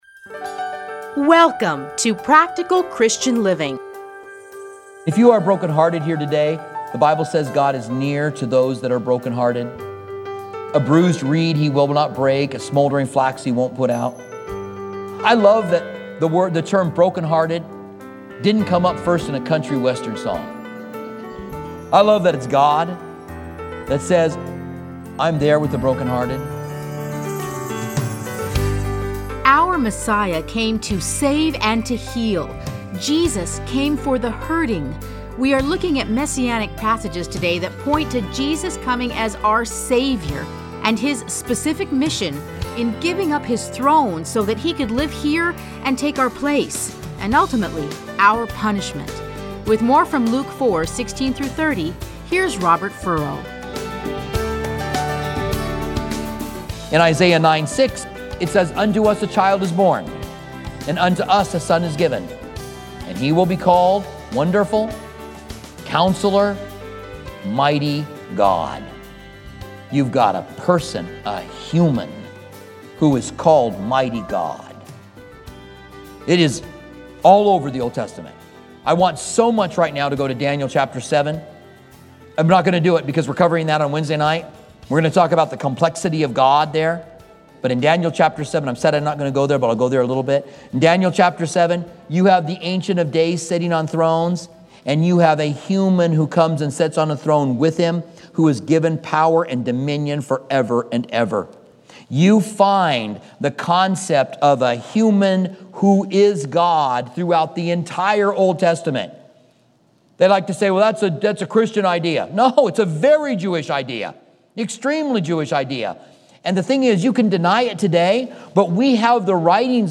Listen to a teaching from Luke 4:16-30.